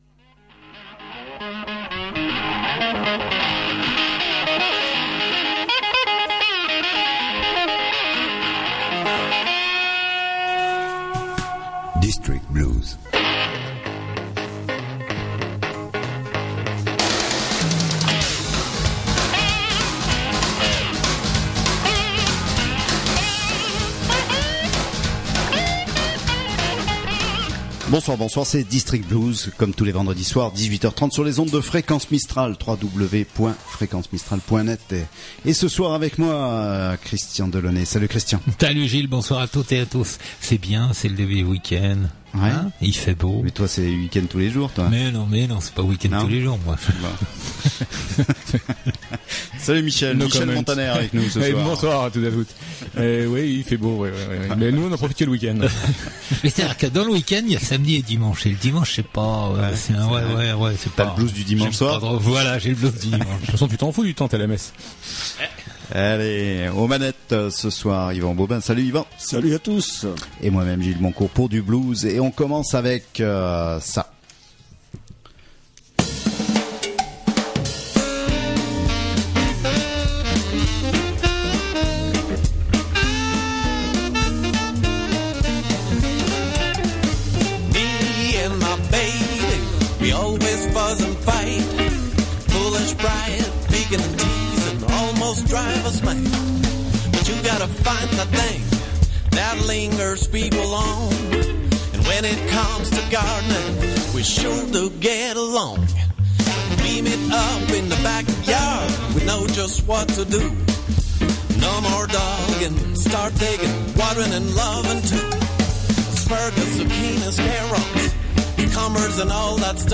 Le blues sous toutes ses formes, le blues sous toutes ses faces, voilà le credo d’Eden District Blues, qu’il vienne de Chicago, de Milan, du Texas ou de Toulouse, qu’il soit roots, swamp, rock ou du delta…